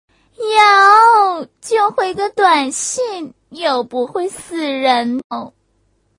风尘味女子撒娇音效免费音频素材下载